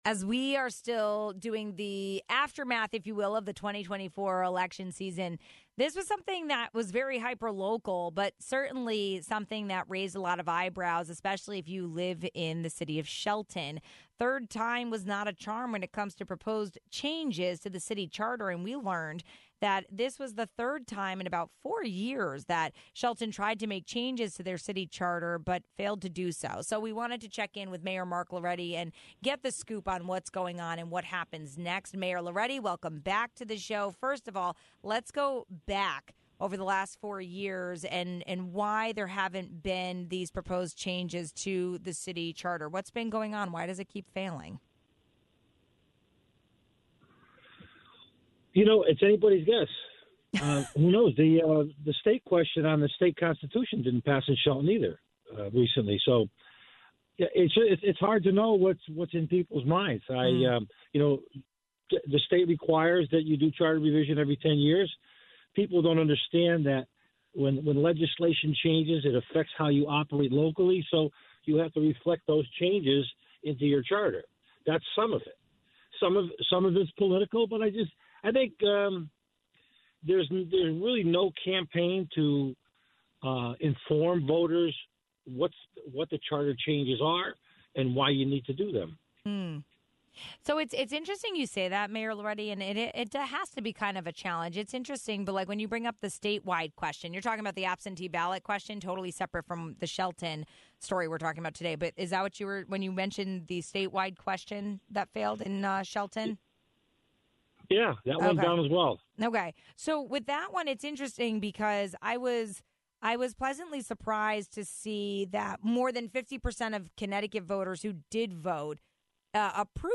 For a third time in four years, Shelton residents overwhelmingly rejected a charter revision for the city. We spoke with Mayor Mark Lauretti about it.